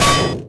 impact_projectile_metal_005.wav